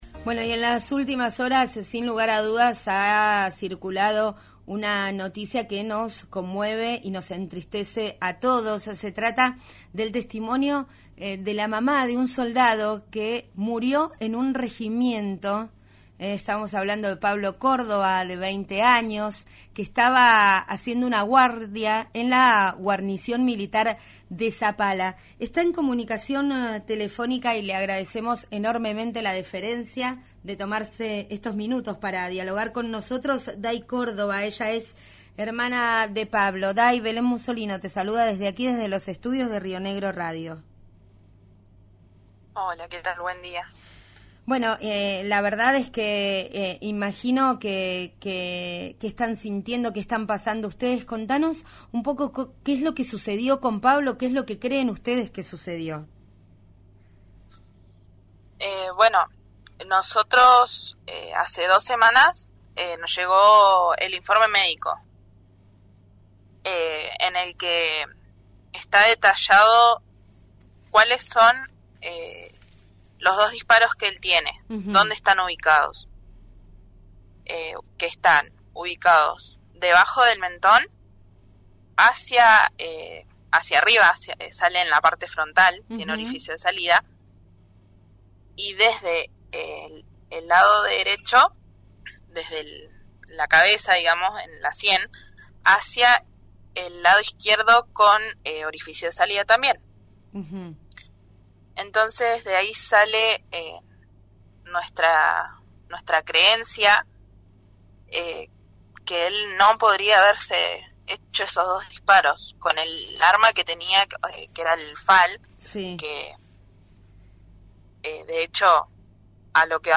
En diálogo con el programa «Ya es tiempo»